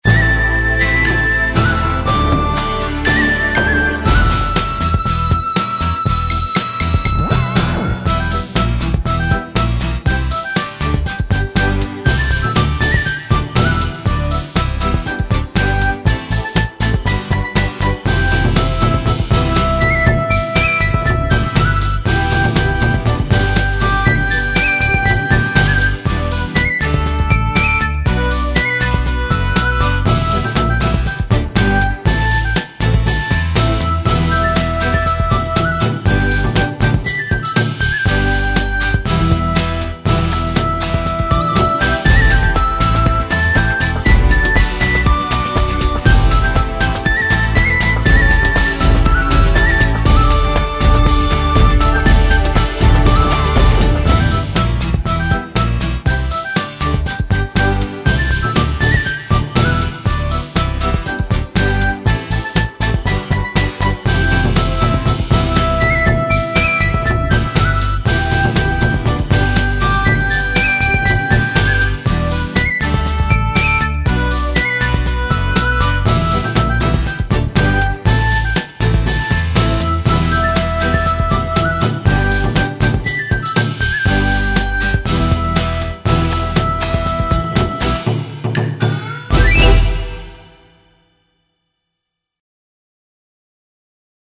阿丑伯(純伴奏版) | 新北市客家文化典藏資料庫